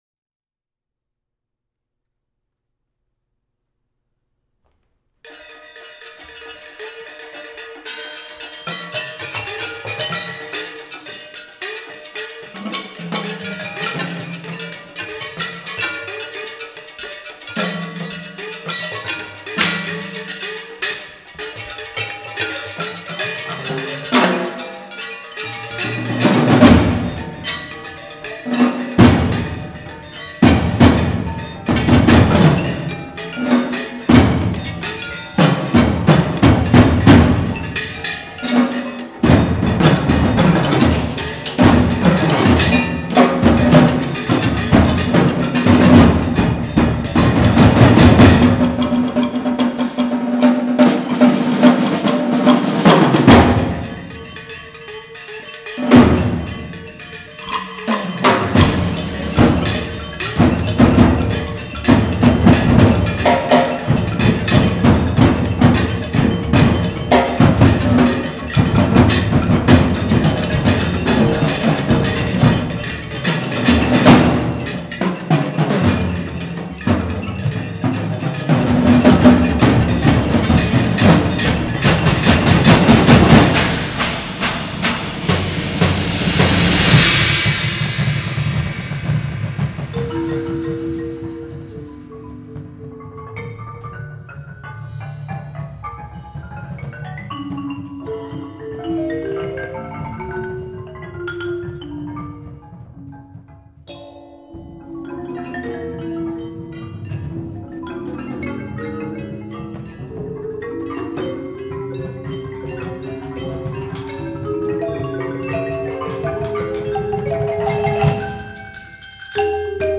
Genre: Percussion Ensemble
# of Players: 16
Player 3 (triangle, bass drum, bongos)
Player 16 (timbales, concert bass drum, hi-hat)